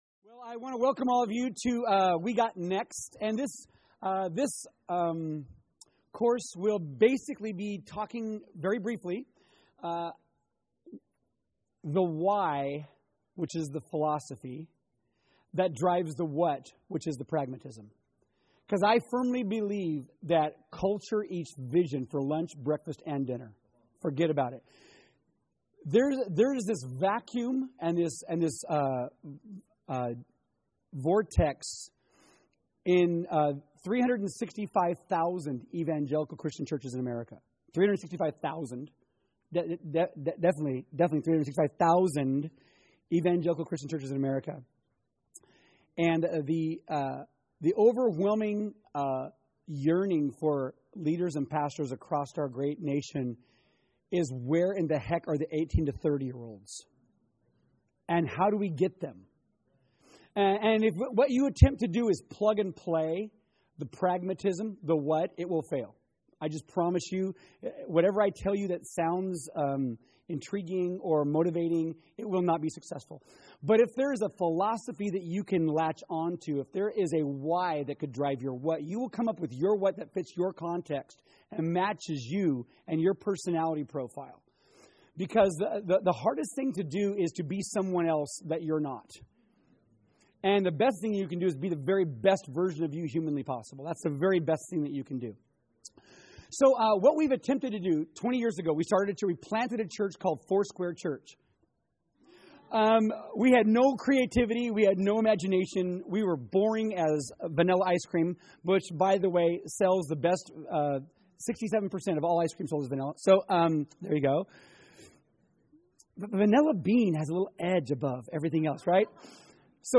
Workshop: We got next